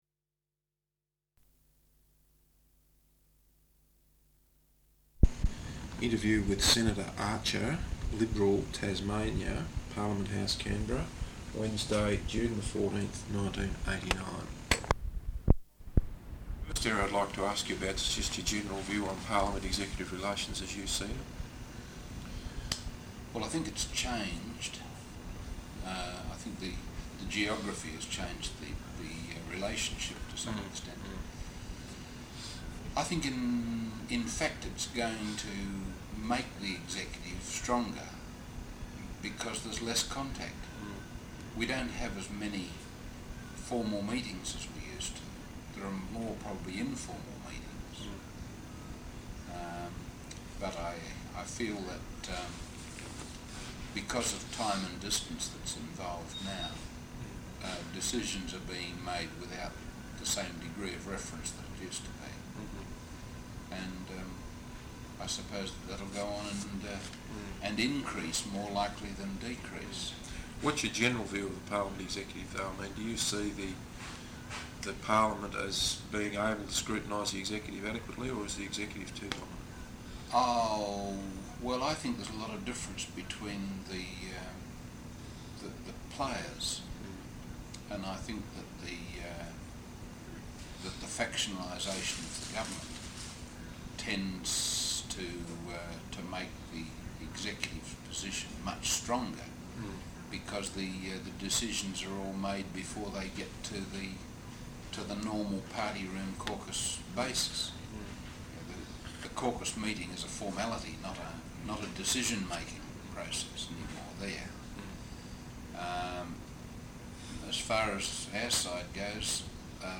Interview with Senator Archer, Liberal Senator for Tasmania, Parliament House, Canberra, Wednesday June 14th 1989.